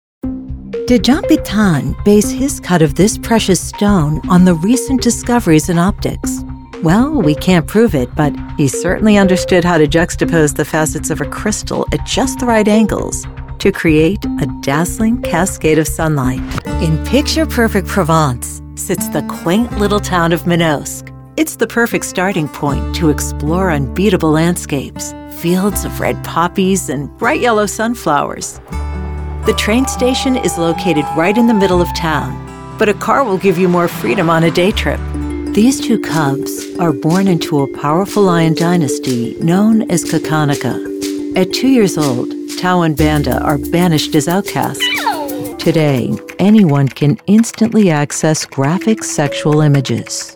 Documentaries
With a warm grounded (North American English) voice, wry smile and a storyteller’s heart, I want people to feel something real when they listen.
Acoustic Paneled and Sound Treated- 12'x6' recording studio and workstation